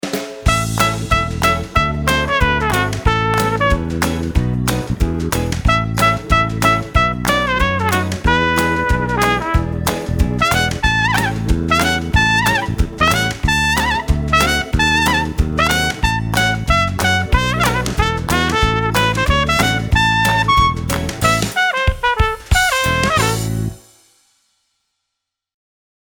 185 BPM